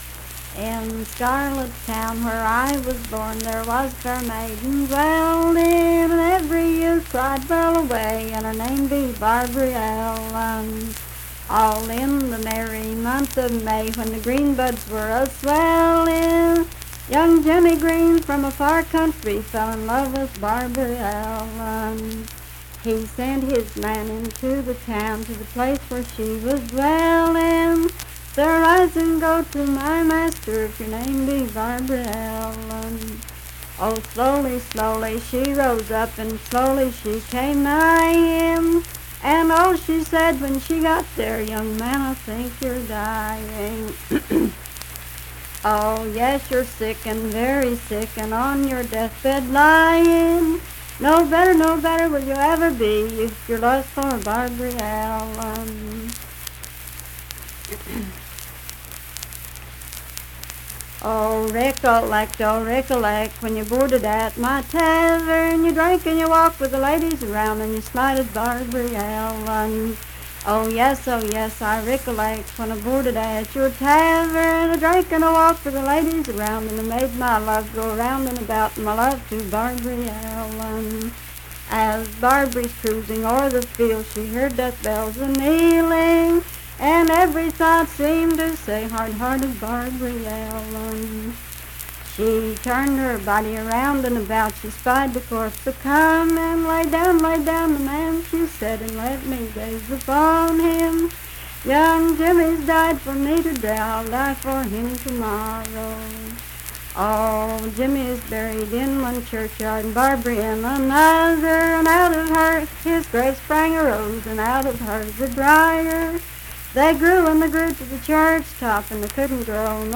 Unaccompanied vocal music
Voice (sung)
Braxton County (W. Va.), Sutton (W. Va.)